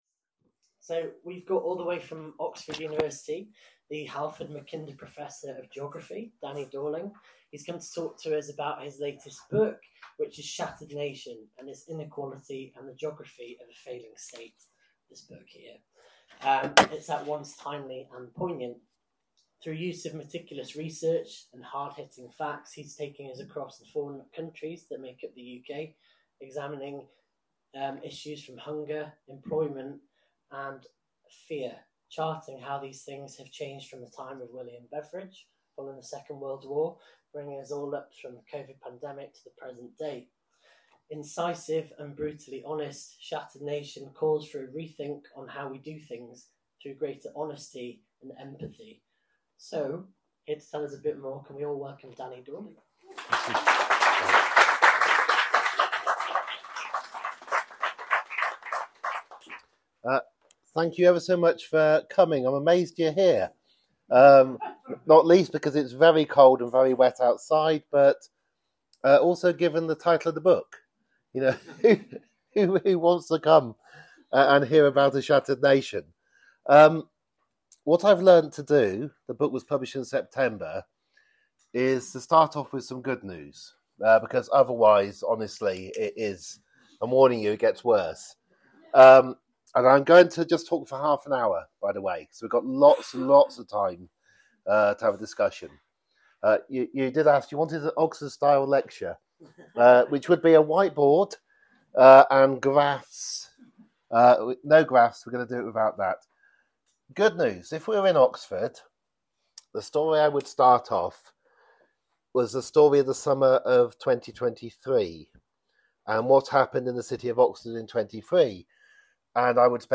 Danny Dorling asking how Britain became so divided. Recorded at Toppings book shop in Edinburgh on 7 December 2023.